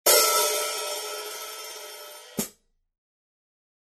Zildjian 14" New Beat Hi-Hat Cymbals